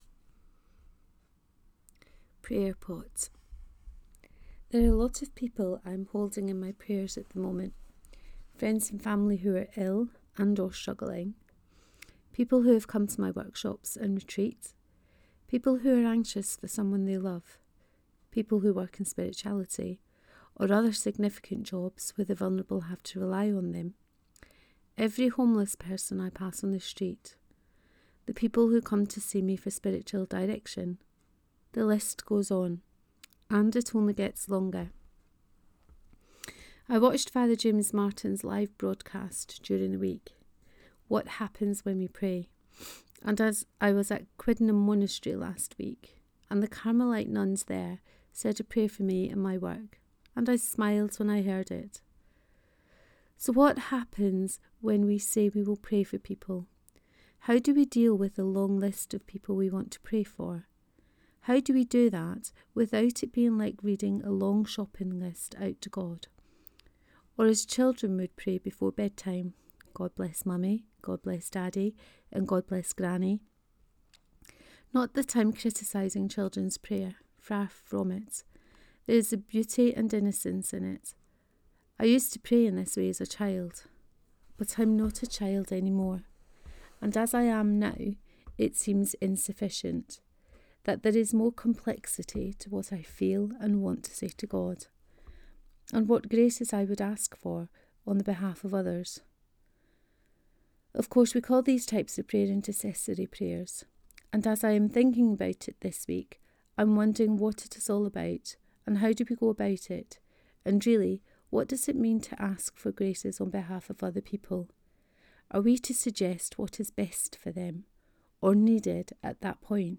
Prayer Pot 1: Reading of this post.